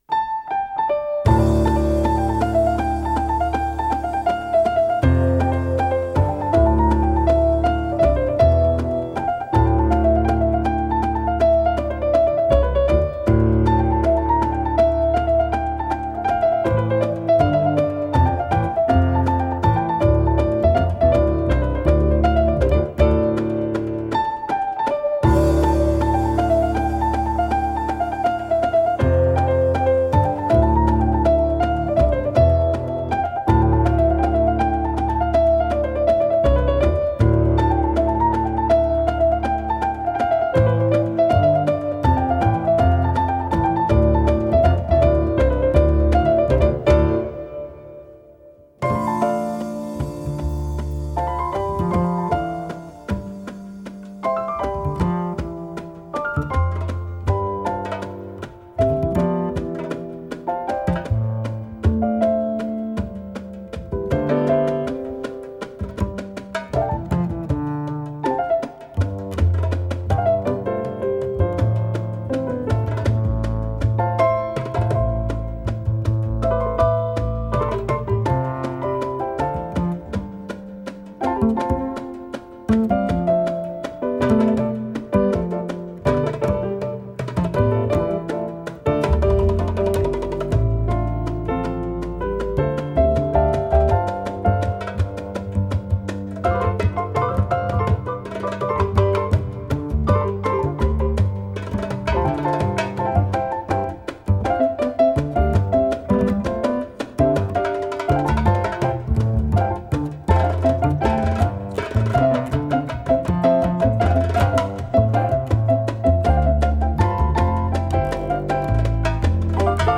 (fortepian, instrumenty klawiszowe, celesta)
(kontrabas, wiolonczela)
(instrumenty perkusyjne)